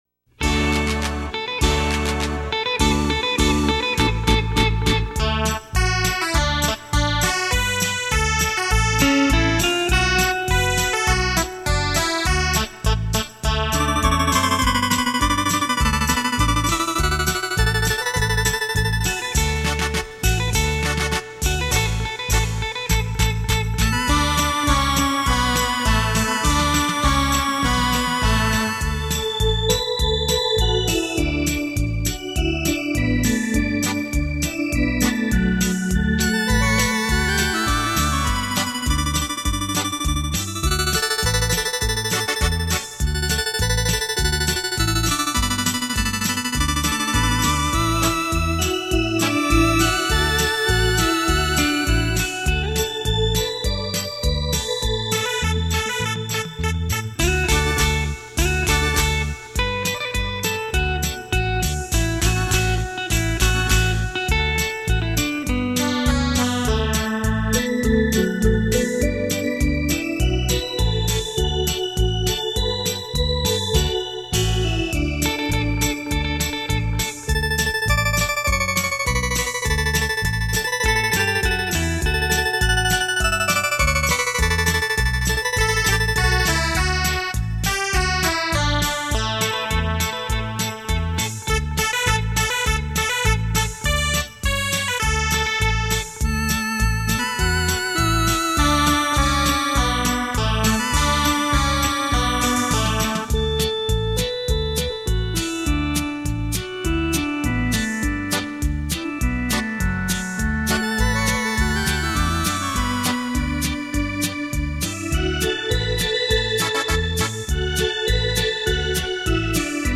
电子琴诠释经典 表现出另一种风情
经典的歌曲 全新的演绎 浪漫双电子琴带你回味往事